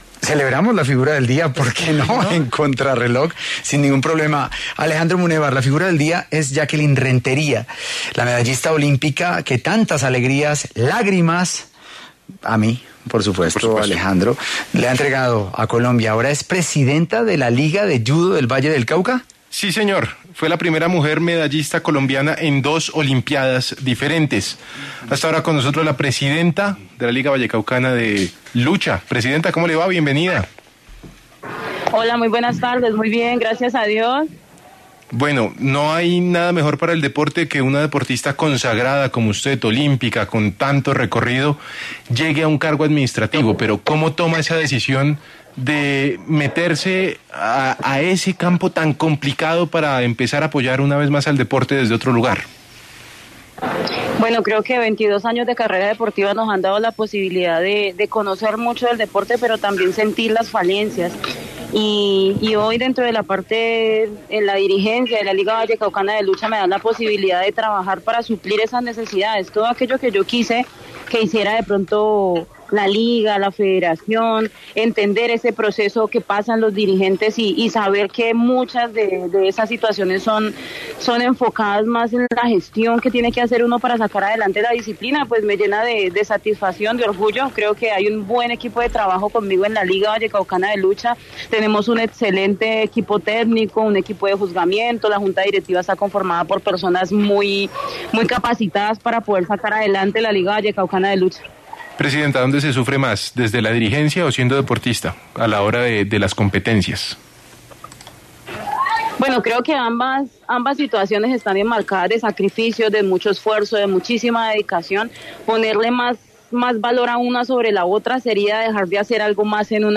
Jackeline Rentería, medallista olímpica y quien ahora es presidenta de la Liga de Lucha del Valle del Cauca, pasó por los micrófonos de Contrarreloj para hablar sobre este cargo tras una vida deportiva llena de éxitos.